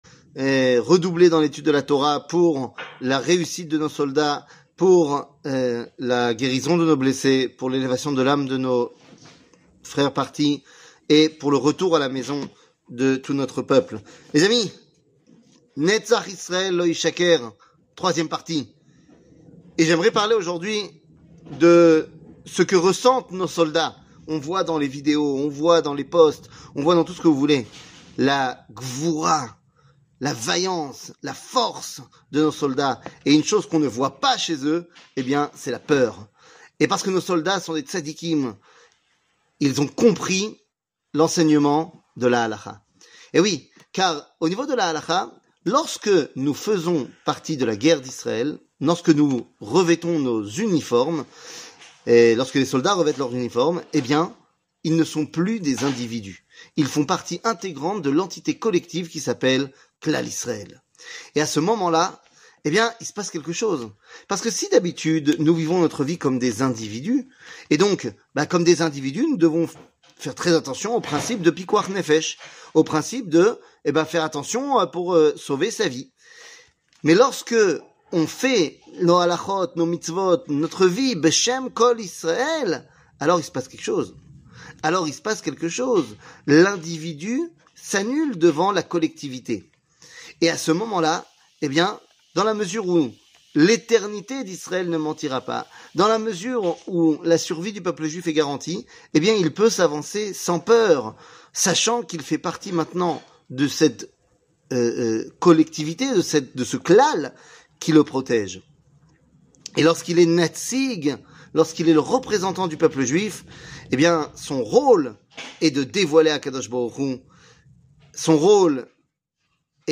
L Eternite d Israel ne mentira pas ! 3 00:04:49 L Eternite d Israel ne mentira pas ! 3 שיעור מ 10 אוקטובר 2023 04MIN הורדה בקובץ אודיו MP3 (4.4 Mo) הורדה בקובץ וידאו MP4 (6.66 Mo) TAGS : שיעורים קצרים